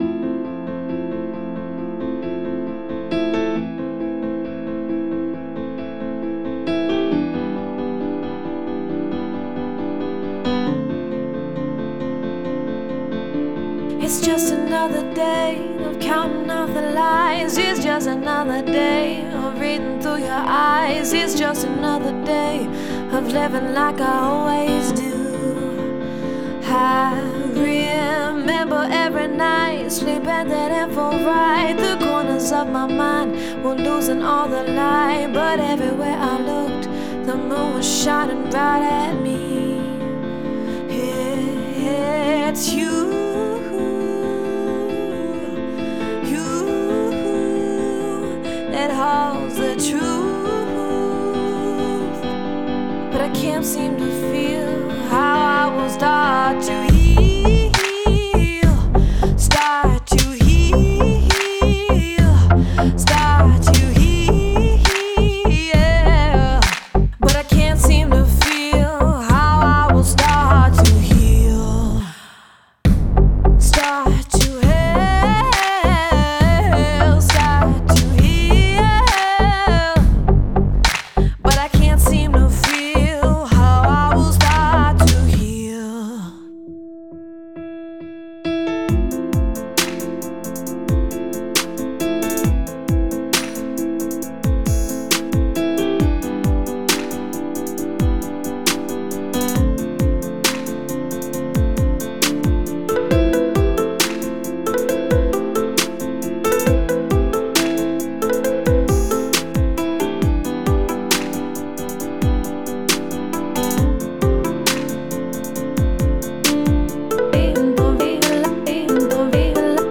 entitled “Heal”. Featuring a catchy piano riff and a clear EDM influence, the track is an impressive accomplishment for someone juggling the rigours of IB.